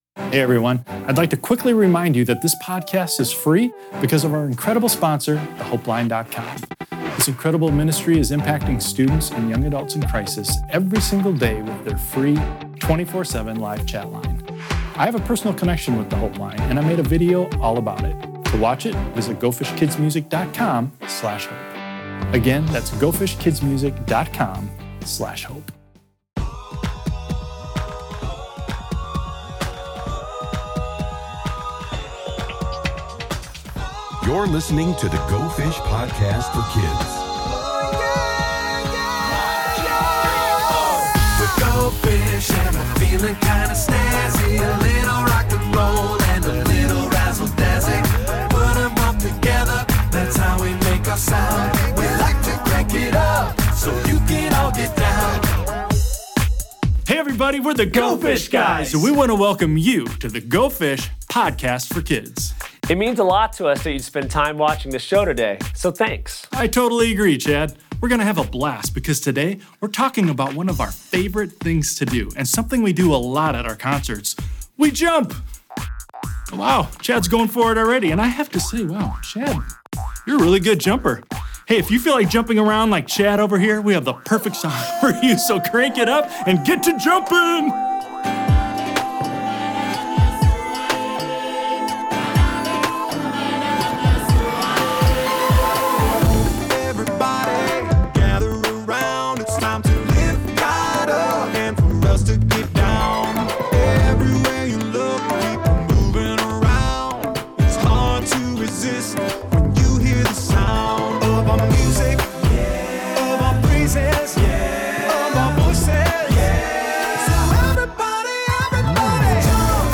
Get ready for a high-energy episode of The Go Fish Podcast for Kids, where things are about to get seriously bouncy! This week, it’s all about one of the Go Fish Guys’ favorite things to do—jumping!
And if you’re in the mood to get moving too, then tune in and get ready for some awesome songs to crank up and jump along to!